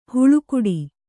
♪ huḷu kuḍi